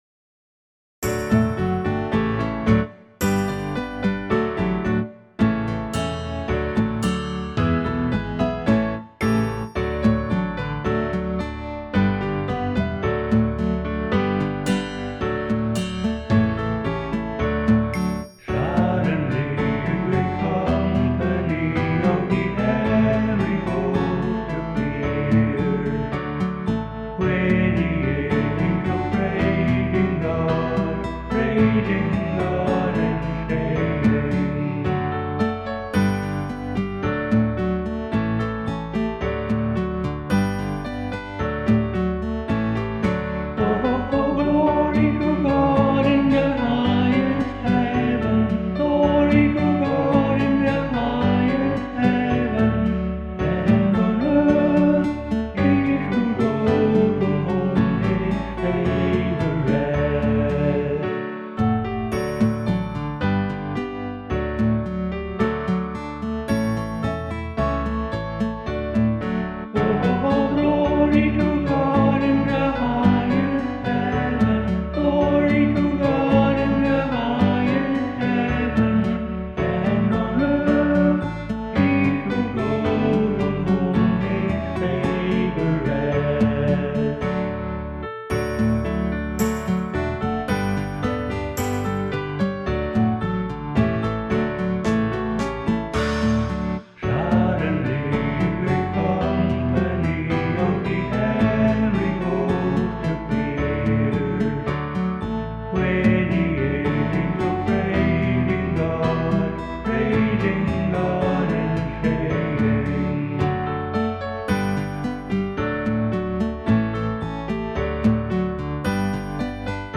MP3 - voice only